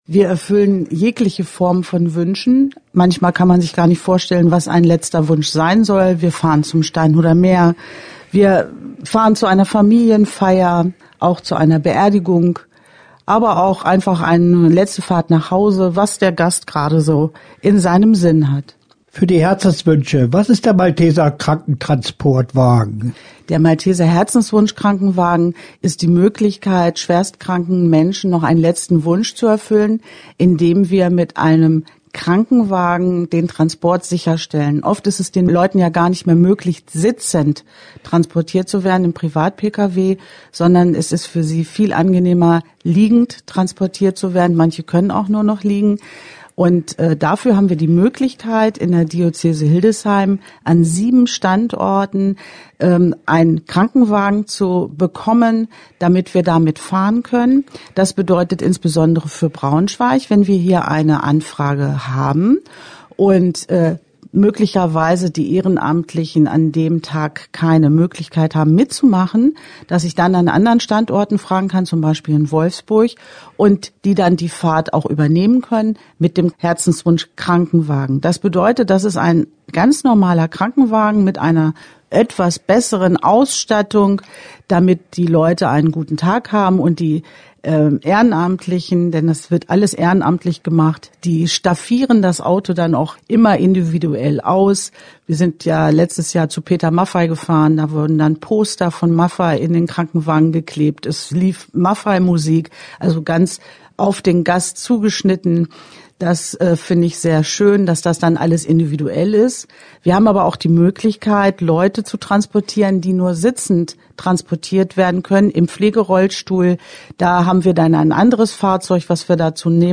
Interview-Herzenswunsch.mp3